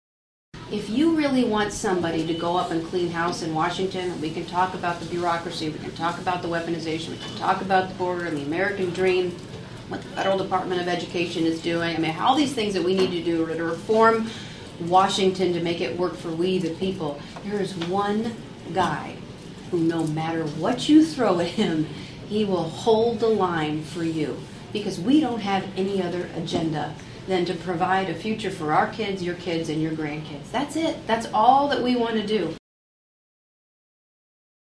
(Atlantic) Casey DeSantis, Florida First Lady and Wife to Republican Presidential Candidate Ron Desantis, made a stop in Atlantic Friday Afternoon.
Casey also spoke on how their only agenda is to “Provide a Future for our kids, your kids, and your grandkids.”